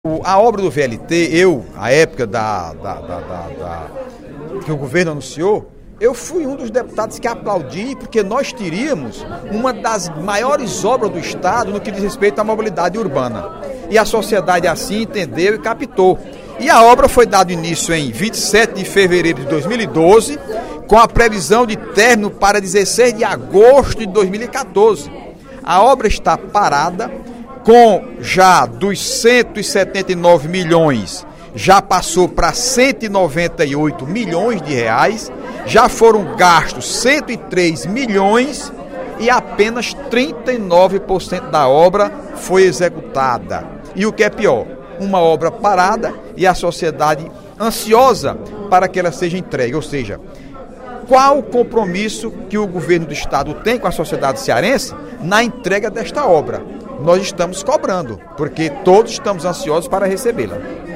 O deputado Heitor Férrer (PDT) encerrou o primeiro expediente da sessão plenária desta terça-feira (04/11) chamando atenção para a paralisação das obras da linha leste do Veículo Leve sobre Trilhos (VLT) Parangaba-Mucuripe. Em pronunciamento no Plenário 13 de Maio, ele informou que o trecho tinha término previsto para 16 de agosto deste ano, porém, até o momento, só atingiu 39% de sua execução física, enquanto já “consumiu” R$ 103 milhões dos R$ 179 milhões orçados inicialmente.